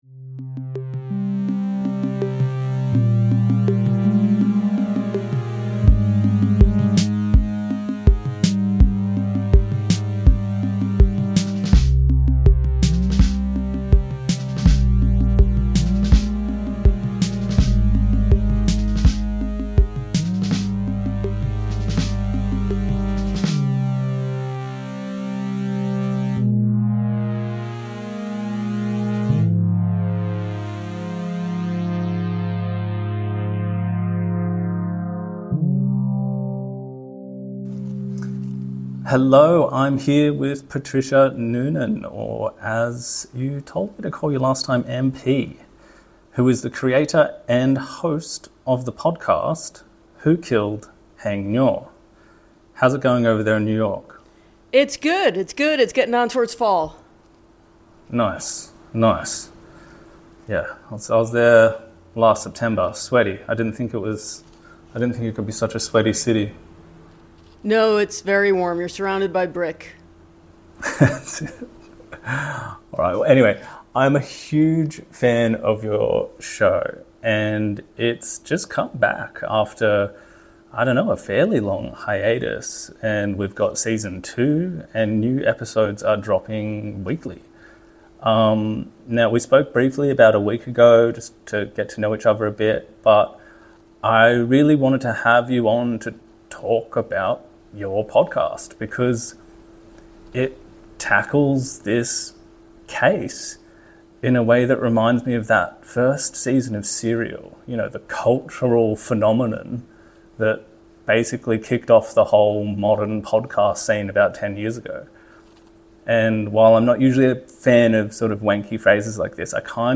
In the Shadows of Utopia: The Khmer Rouge and the Cambodian Nightmare / Interview: Who Killed Haing Ngor?